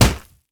punch_grit_wet_impact_07.wav